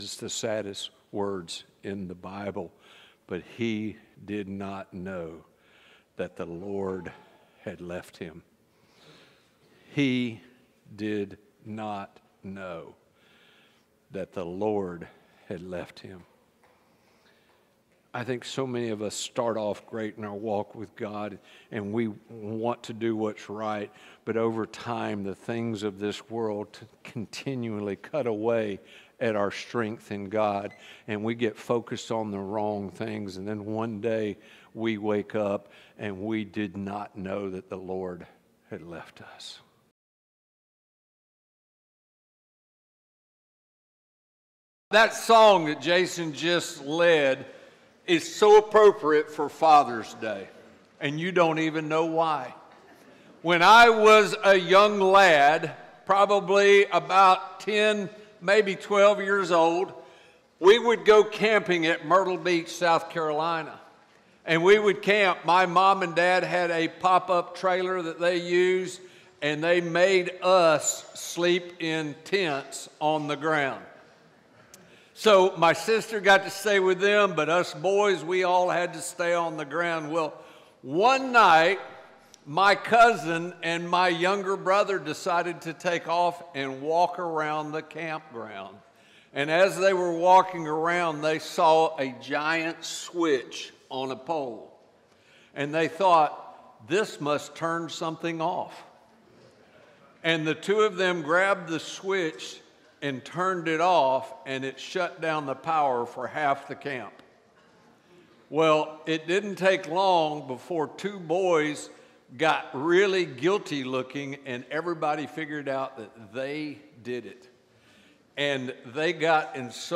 Recording from North Tampa Church of Christ in Lutz, Florida.